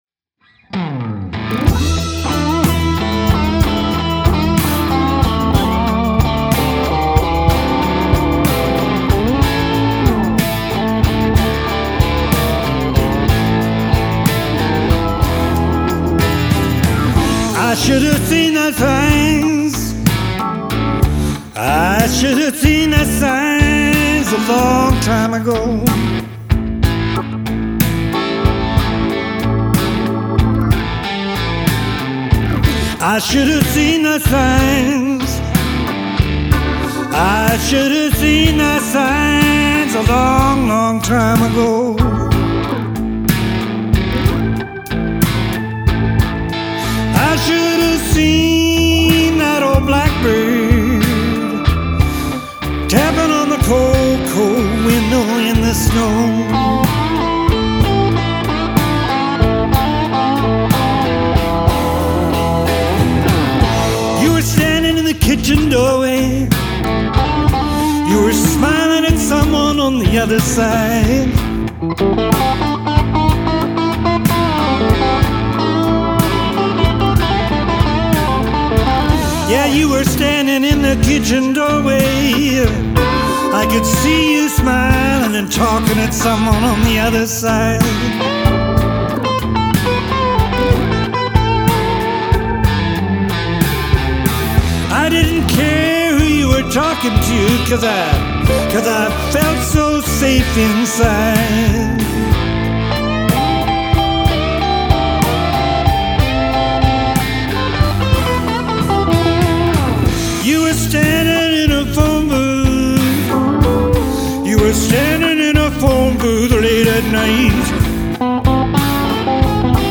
came in and threw on an arduous electric guitar track.
layered all this live music and musical energy around it